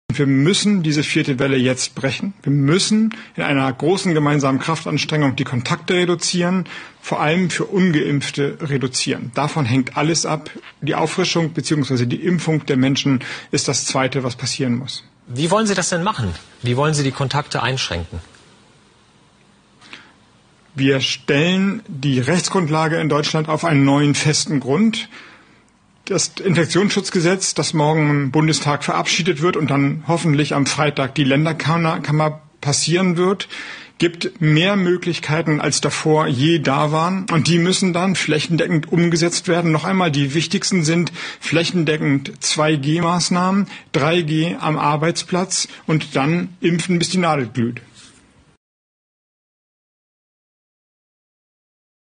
Klassiker Impfen, bis die Nadel glüht 🤡 Alleine wegen dieses verheerenden Interviews vom November 2021, welches im Rückblick enorm viel Lügen-Propaganda enthält, müsste Habeck zurücktreten...